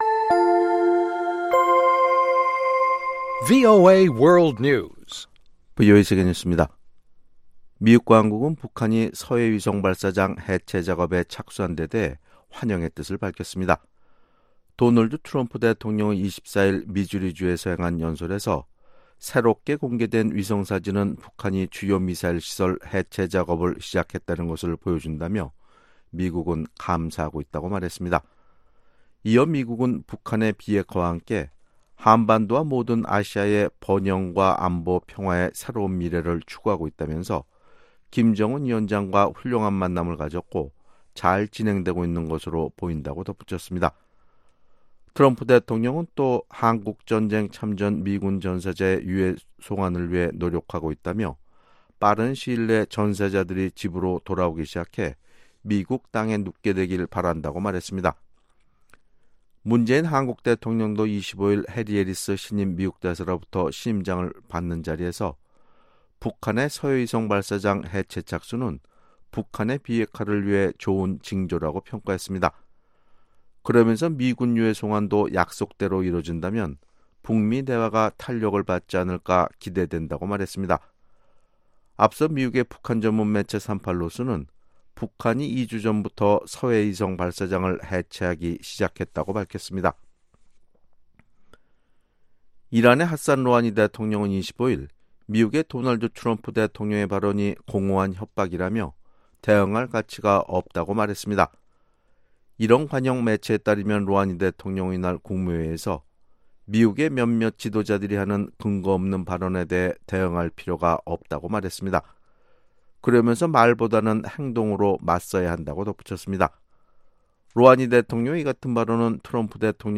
VOA 한국어 아침 뉴스 프로그램 '워싱턴 뉴스 광장' 2018년 7월 26일 방송입니다. 도널드 트럼프 미국 대통령이 북한의 서해 위성발사장 해체 작업을 긍정적으로 평가하면서 미군 참전용사 유해 송환 기대를 전했습니다. 한국 국방부가 비무장지대(DMZ)에서 병력과 장비를 단계적으로 철수하는 방안을 추진하고 있다고 밝혔습니다.